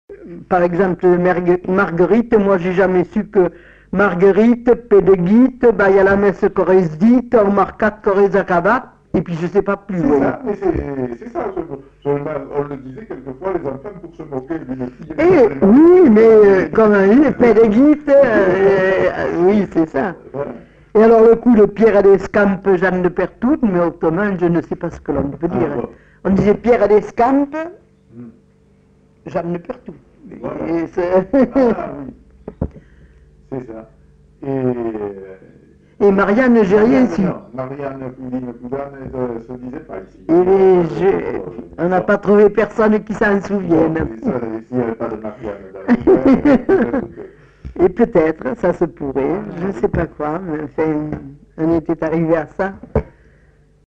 Lieu : Grignols
Effectif : 1
Type de voix : voix de femme
Production du son : récité
Classification : formulette enfantine